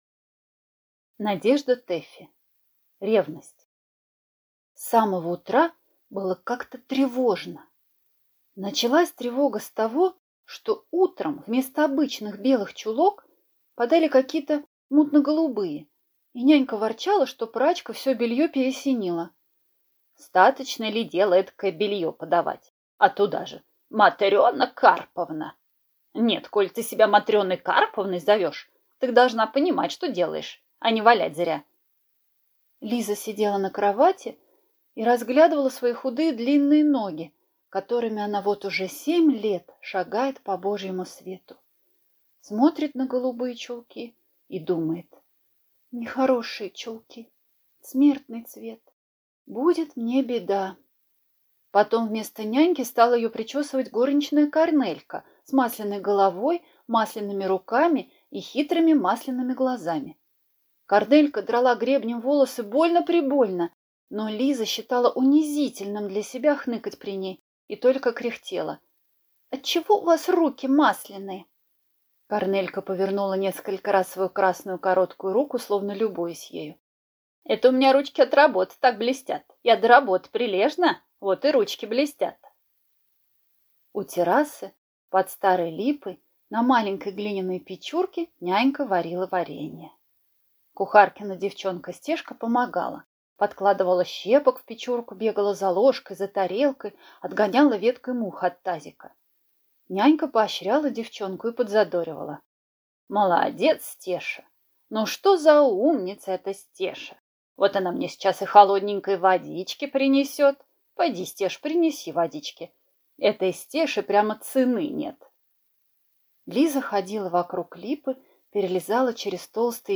Аудиокнига Ревность | Библиотека аудиокниг